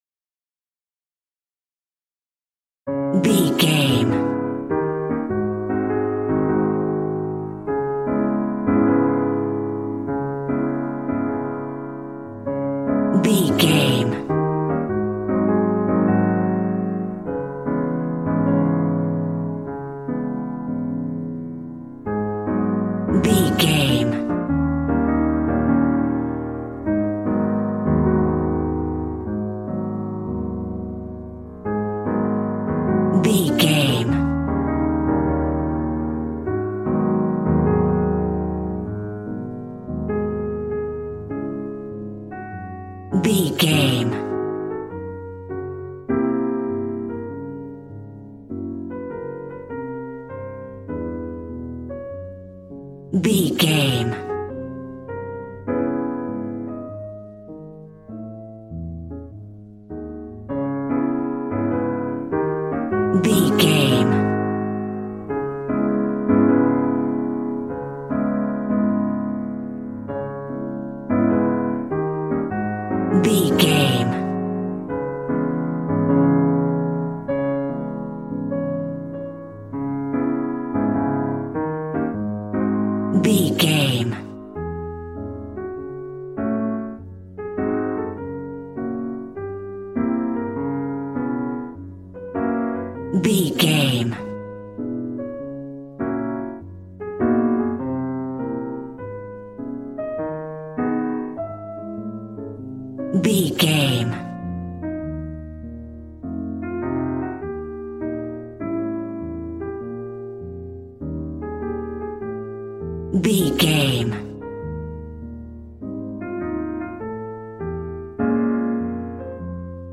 Aeolian/Minor
A♭
smooth
piano
drums